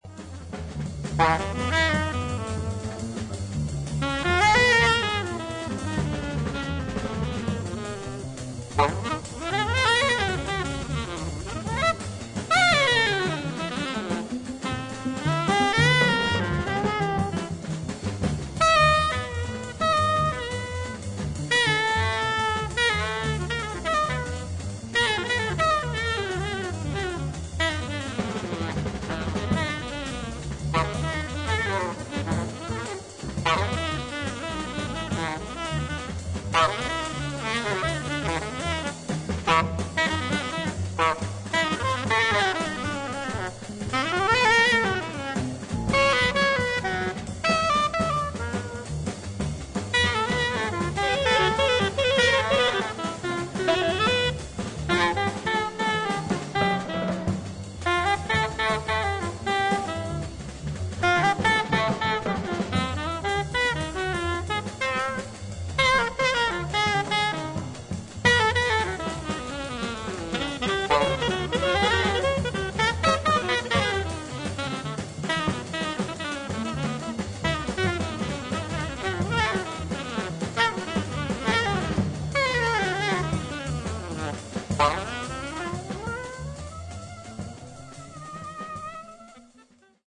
ブルースのモードを基調にしながらも、型に縛られない自由な演奏が魅力の名盤です。